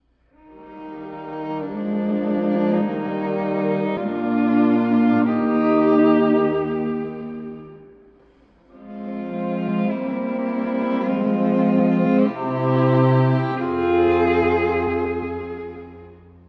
室内楽曲の紹介
↑古い録音のため聴きづらいかもしれません！（以下同様）
教会音楽のような、厳かな響きが特徴的です。
Es-dur（変ホ長調）の響きが、祈り、幻想へと誘います。